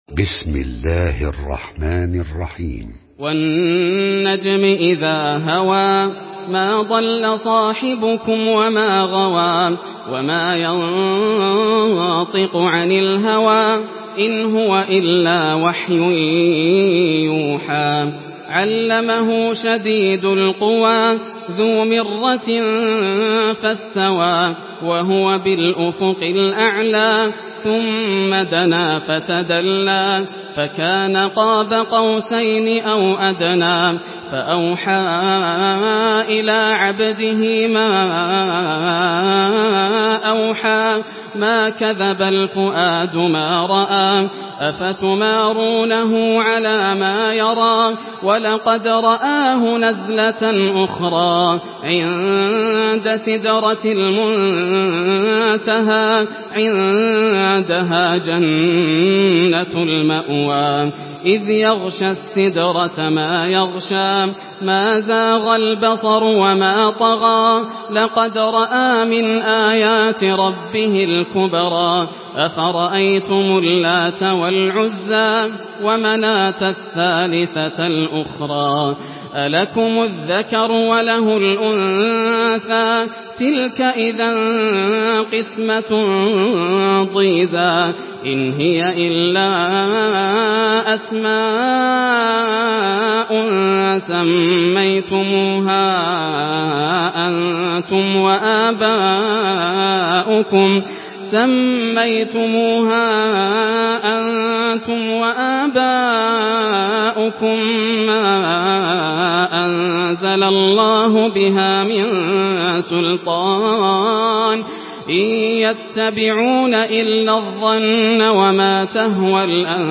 Surah আন-নাজম MP3 in the Voice of Yasser Al Dosari in Hafs Narration
Surah আন-নাজম MP3 by Yasser Al Dosari in Hafs An Asim narration.
Murattal Hafs An Asim